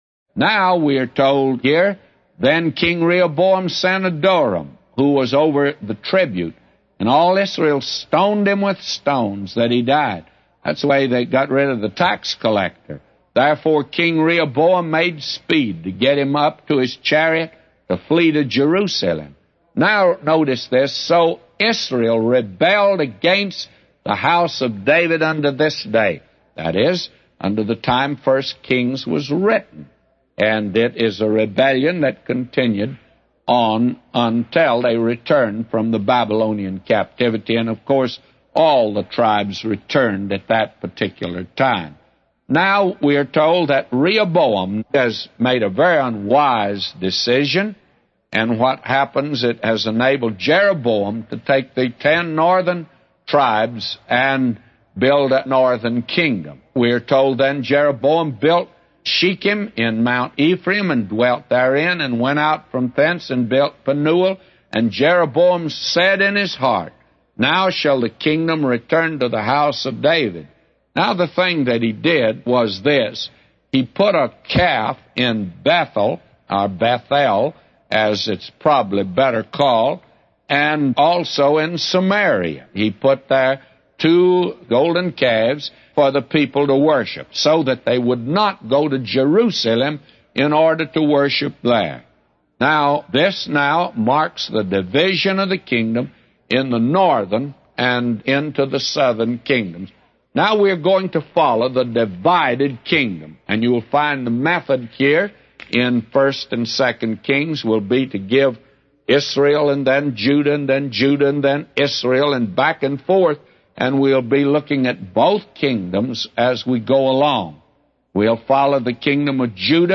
A Commentary By J Vernon MCgee For 1 Kings 12:18-999